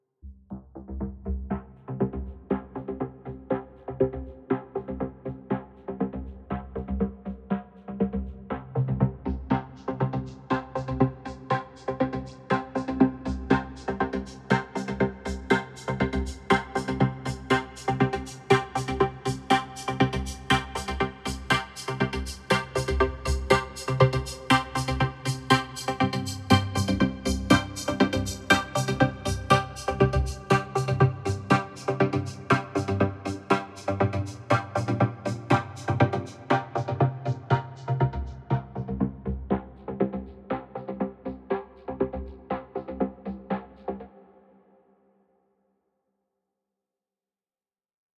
SOS Tutorial 143 Ableton Live - Synth Drums mit Vocoder und Operator